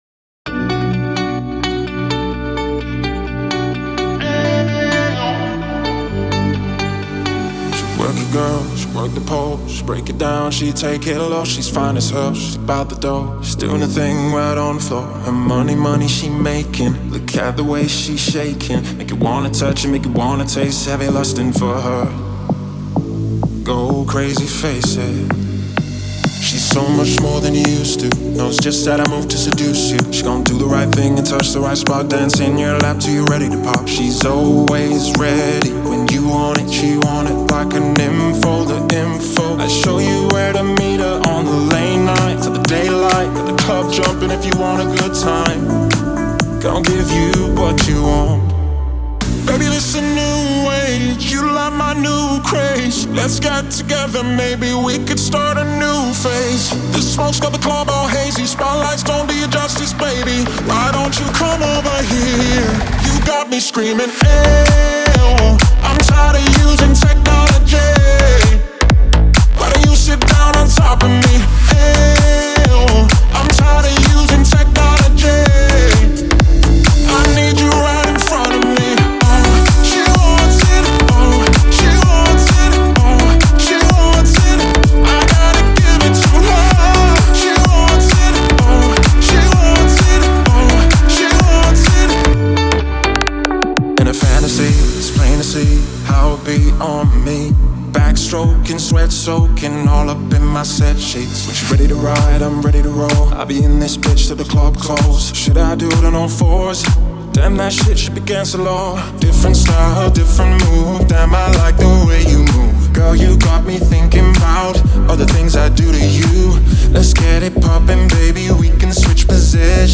Звучание трека насыщенное и динамичное